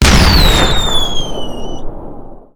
general / combat / vehicles / shell.wav
shell.wav